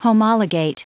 Prononciation du mot homologate en anglais (fichier audio)
Prononciation du mot : homologate